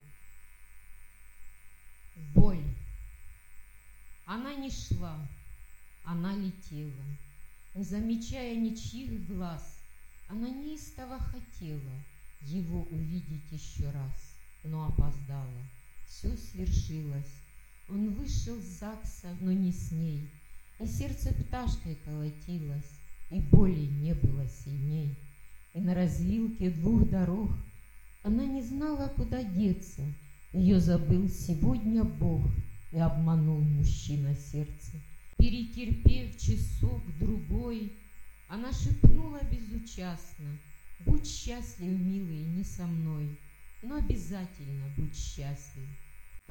Стих начитан автором произведения